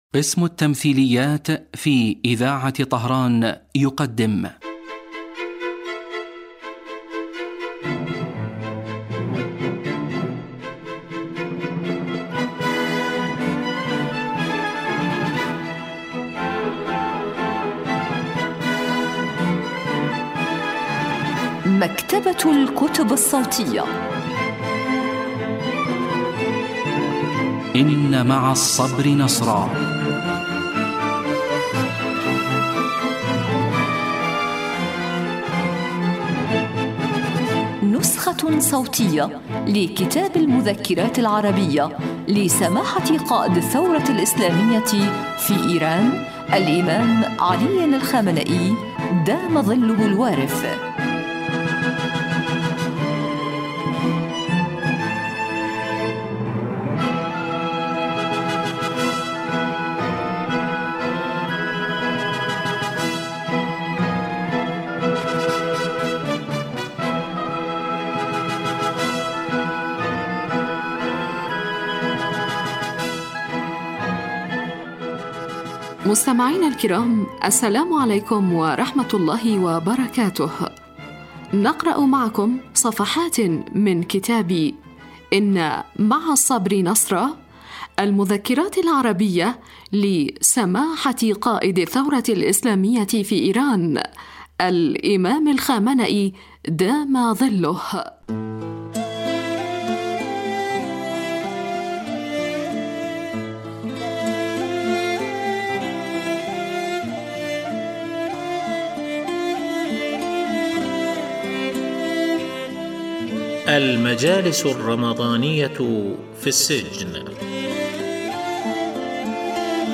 إذاعة طهران- إن مع الصبر نصرا: نسخة صوتية لكتاب المذكرات العربية لقائد الثورة الإسلامية الإمام الخامنئي (دام ظله).